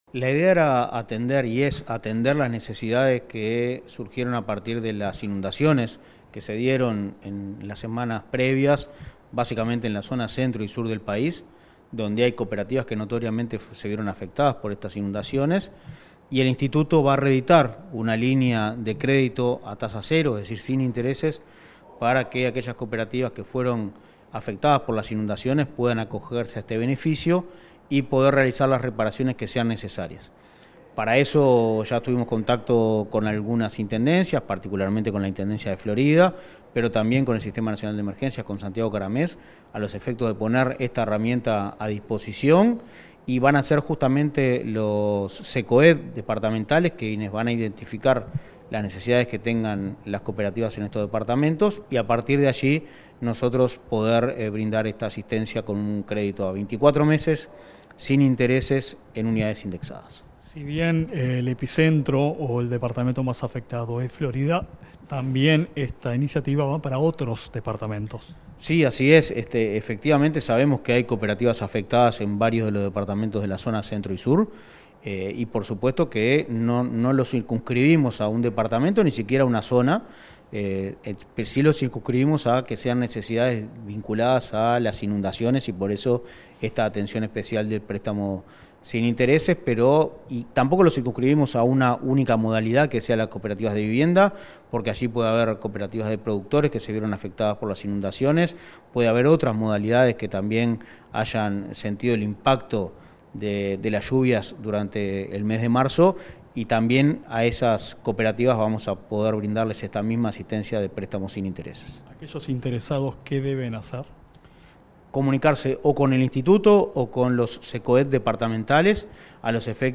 Entrevista al presidente de Inacoop, Martín Fernández